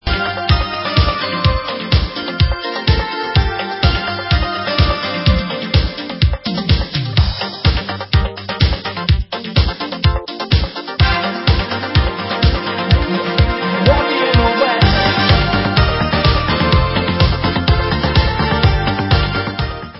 Vocal Extended